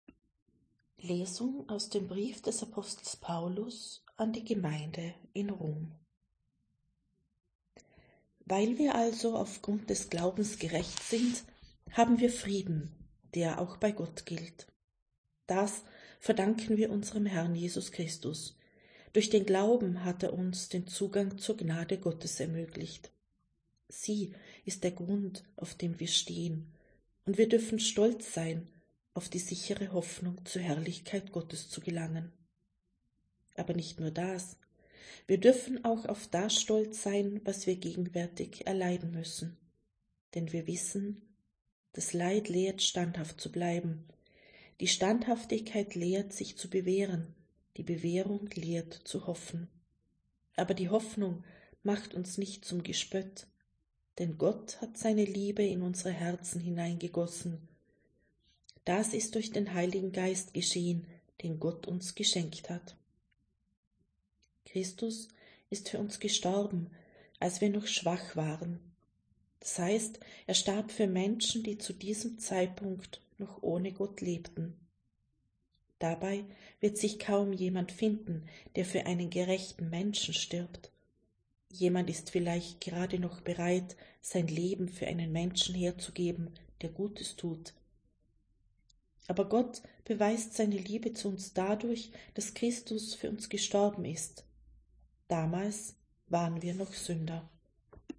Wenn Sie den Text der 2. Lesung aus dem Brief des Apostels Paulus an die Gemeinde in Rom anhören möchten: